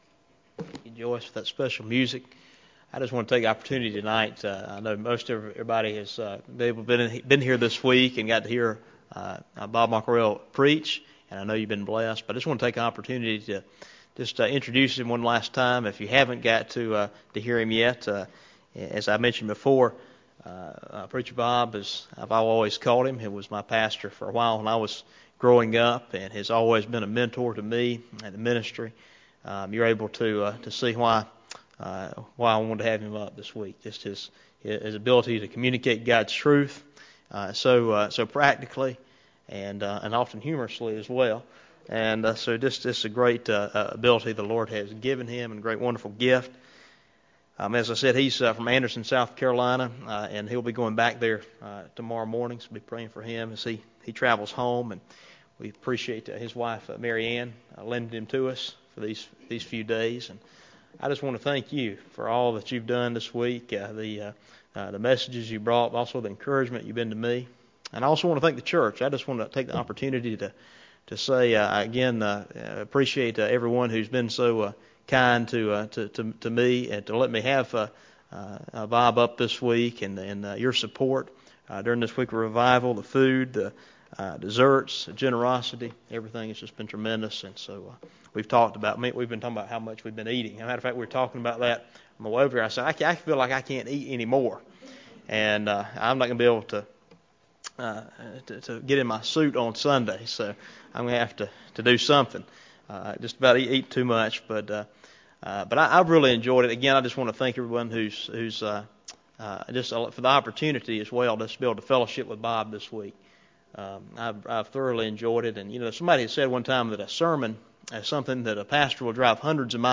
Fall 2015 Revival Sunday Morning